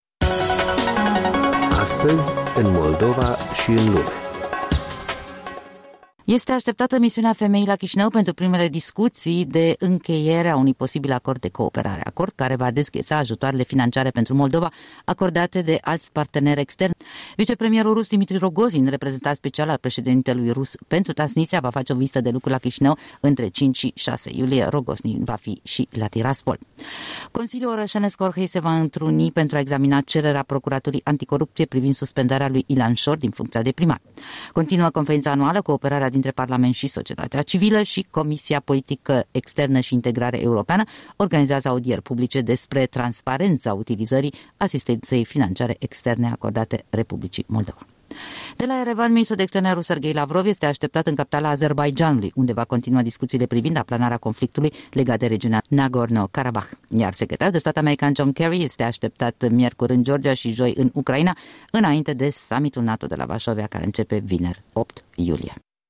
Agenda principalelor evenimente anunțate ale zilei.